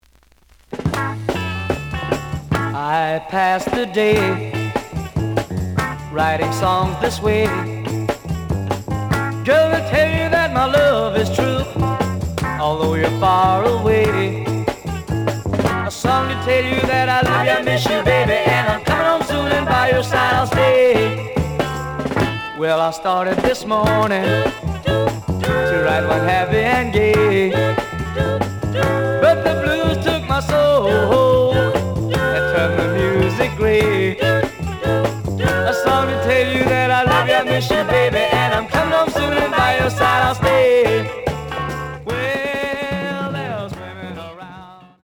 試聴は実際のレコードから録音しています。
●Genre: Rhythm And Blues / Rock 'n' Roll
●Record Grading: VG+ (両面のラベルに若干のダメージ。盤に歪み。多少の傷はあるが、おおむね良好。)